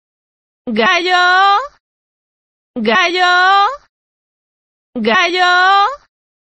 Tanto en Google Maps como en el traductor de Google, la palabra "gallo" se pronuncia de una curiosa manera que ya se ha hecho viral en redes sociales
Así se pronuncia gallo en Google
Es curioso además que este fallo en la pronunciación de la palabra "gallo", alargando la terminación de la palabra hasta un punto humorístico, solo se produce en ordenadores o dispositivos Android, pero no en telefónos iOS.